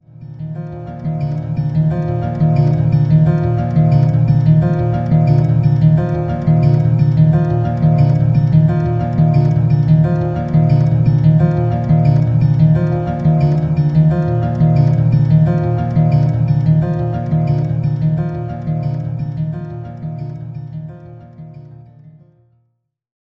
The third one is the loop pitched down 40% with correction to keep the original tempo